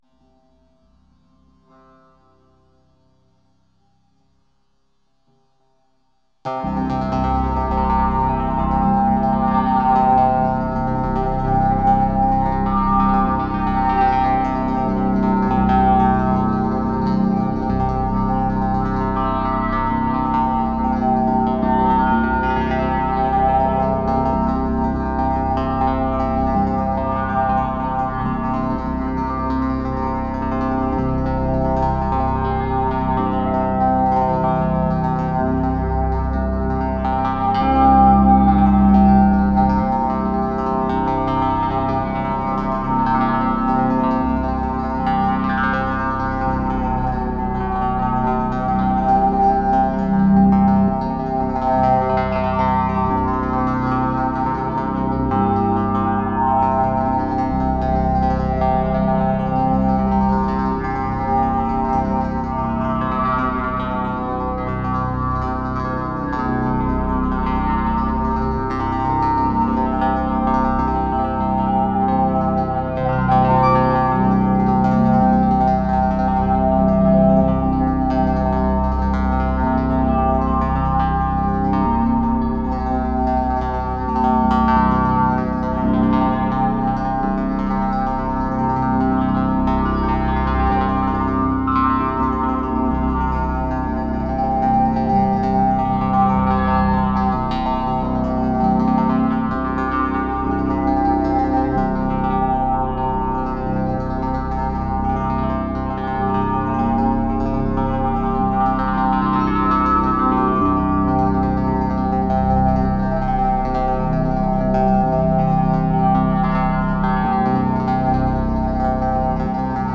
ディープ・リスニングや瞑想に、親和性の高い作品だと思います。
たいへんデリケートで複雑なパンニングと、スペクトル分布を特徴とするアルバムです。
ギター愛好家の方々にはもちろん、現代音楽、先端的テクノ、実験音楽をお好きな方々にもお薦めのアルバムです。